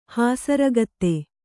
♪ hāsaragate